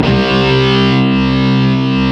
Index of /90_sSampleCDs/Roland LCDP02 Guitar and Bass/GTR_Distorted 1/GTR_Power Chords
GTR PWRCHR02.wav